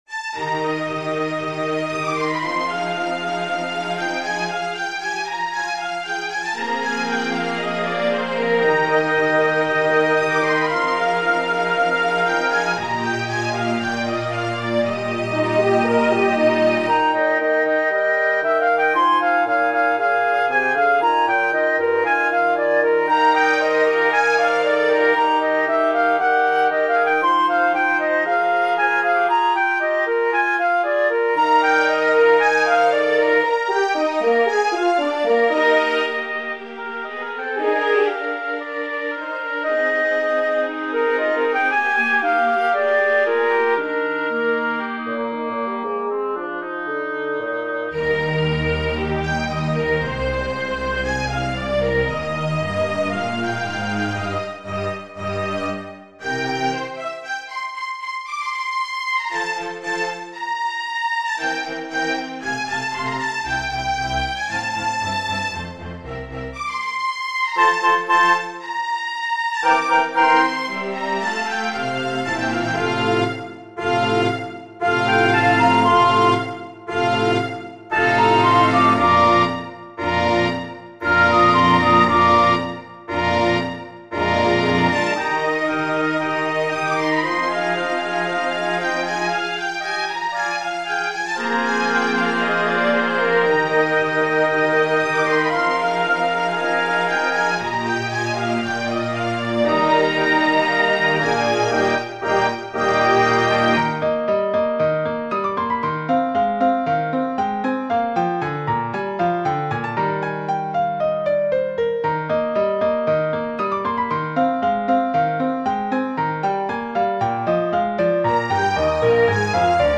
Piano Concerto in E flat major Movement 1